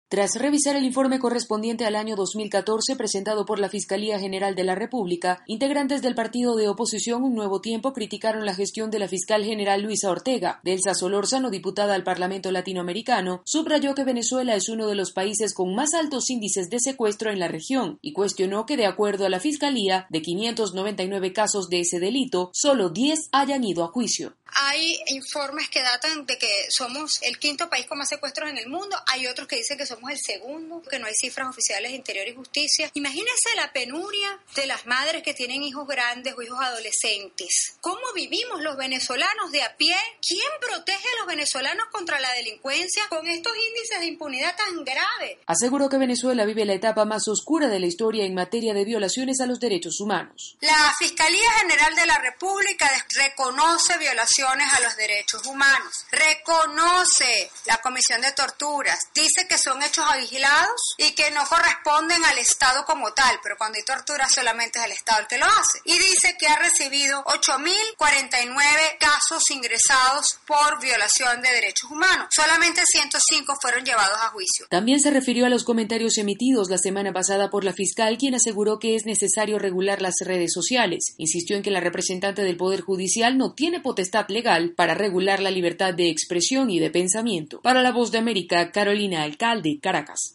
En Venezuela representantes de la oposición condenan los altos niveles de impunidad que se registran en el país. Desde Caracas informa